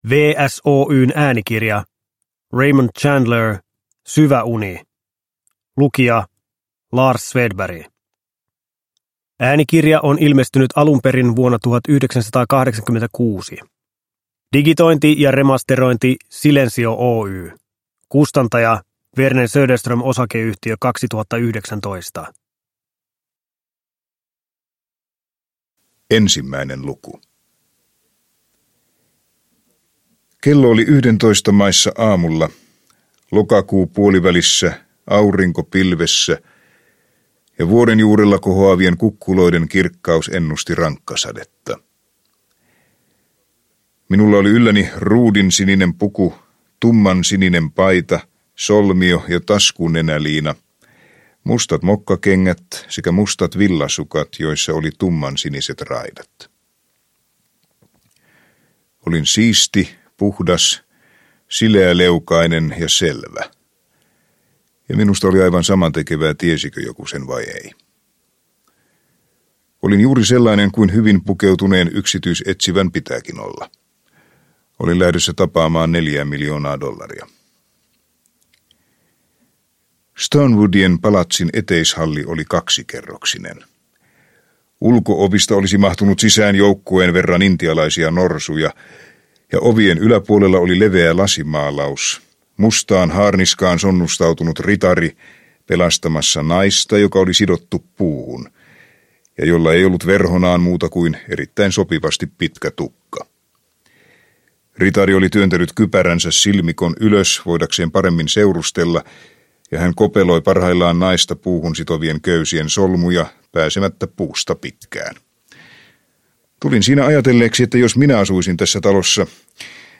Syvä uni – Ljudbok – Laddas ner